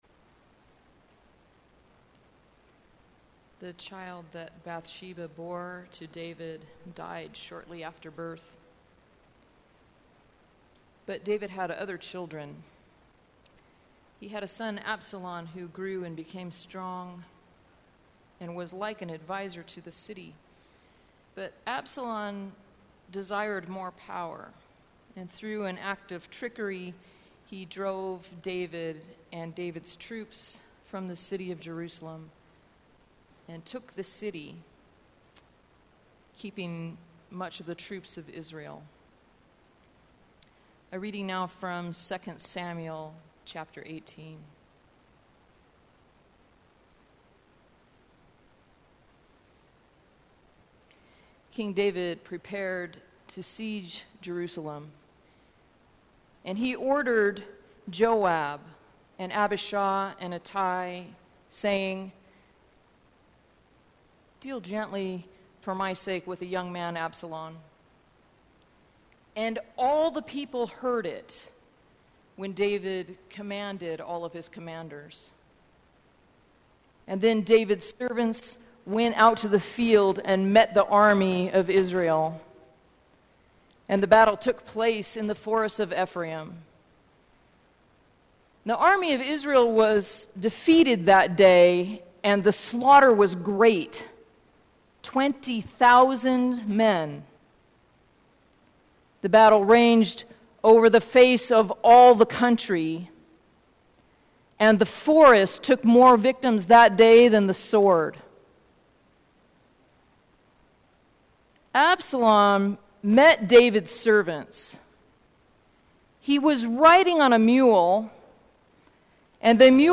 Sermons | Church of the Cross